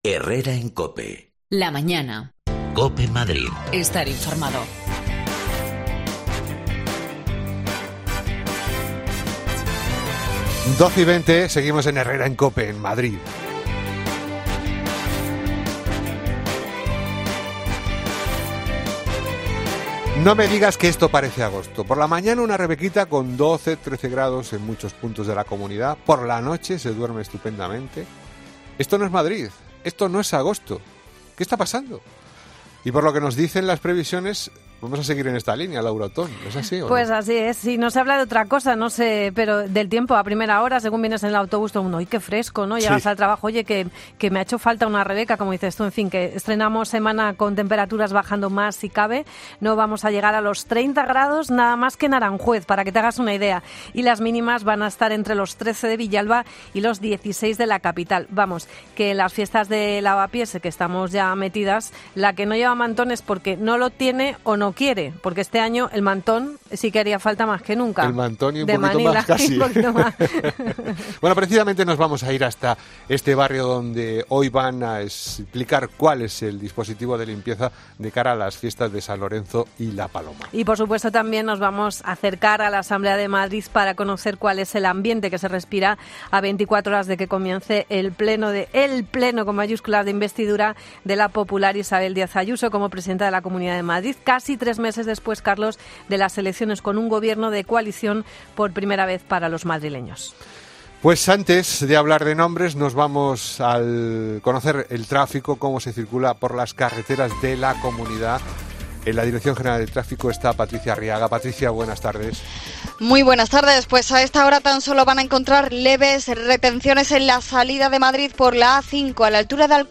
se desplaza a Lavapiés para contarnos el ambiente en las fiestas de Madrid.